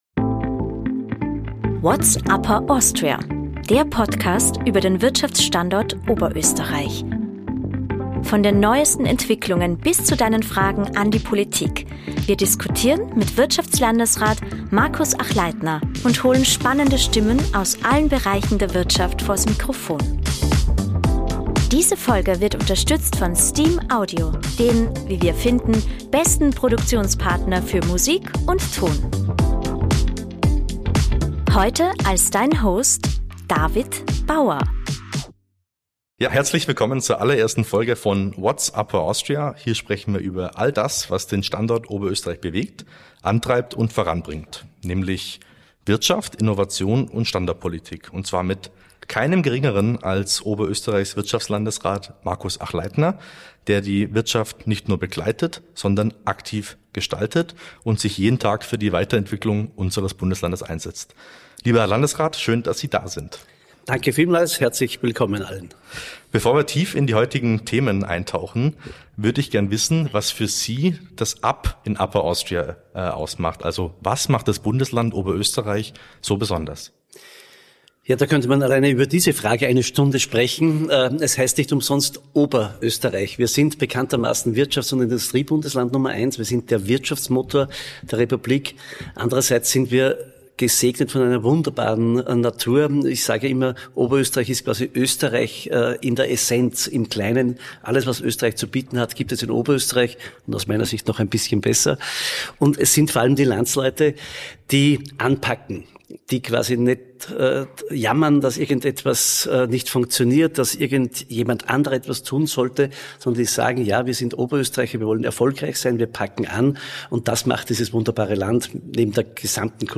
All das verrät er uns im Podcast-Interview. Außerdem stellt er sich – wie in jeder Folge – einer Reihe an Entweder-Oder-Fragen und reagiert auf die Schlagzeile des Monats sowie Hörerfragen aus der Community.